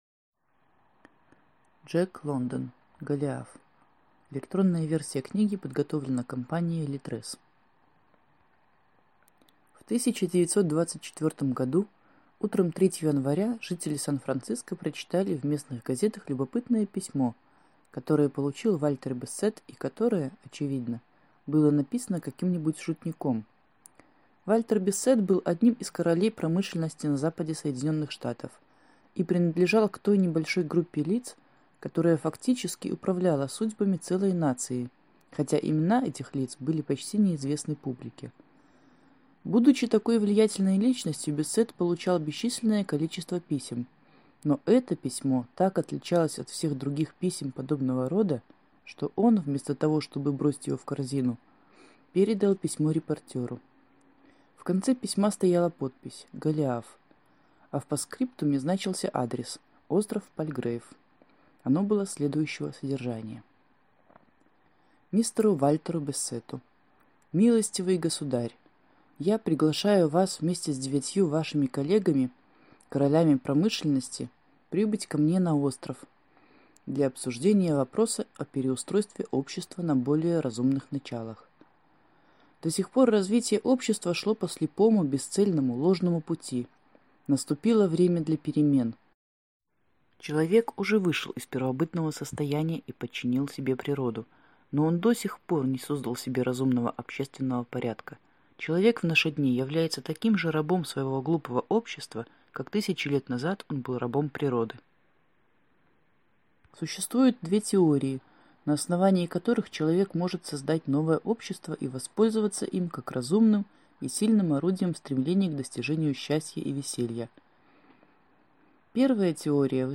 Аудиокнига Голиаф | Библиотека аудиокниг